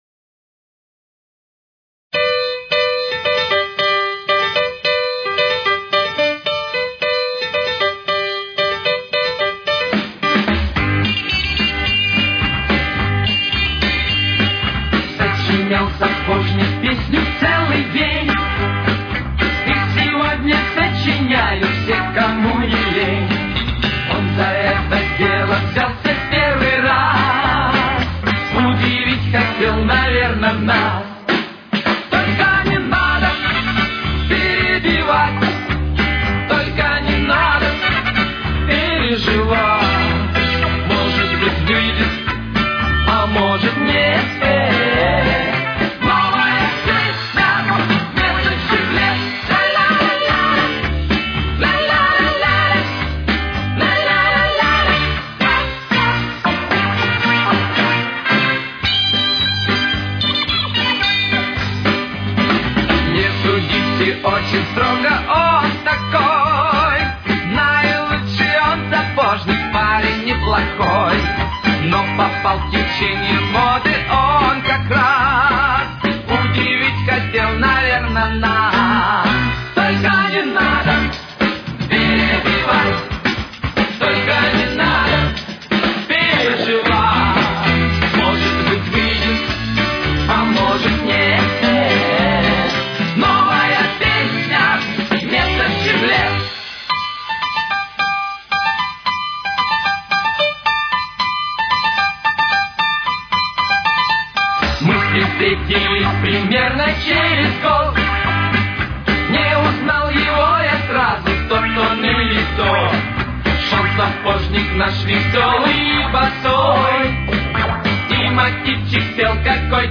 Тональность: Соль мажор. Темп: 115.